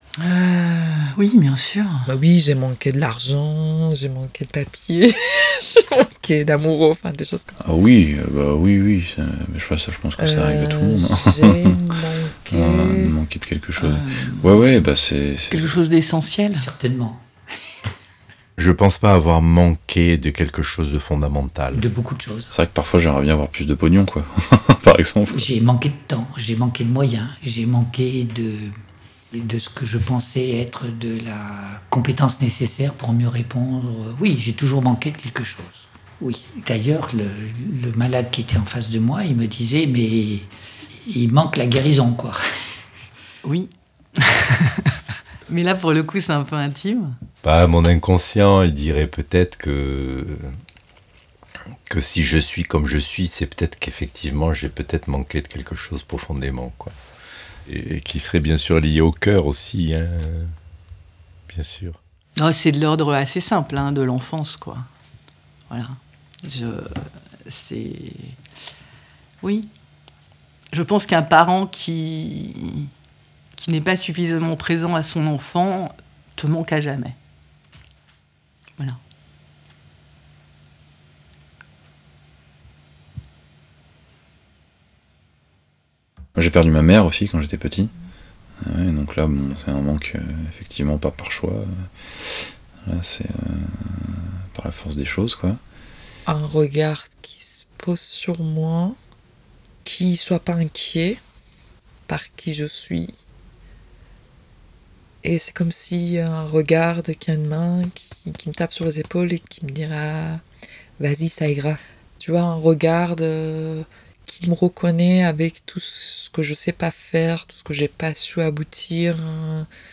Montages d’extraits d’interviews réalisées pour le spectacle CE QU’IL NOUS FAUT !
Ce que vous allez entendre est en cours de montage, et non mixé.
Où les paroles se croisent, les personnages se répondent, et s’incarnent dans des haut-parleurs personnages.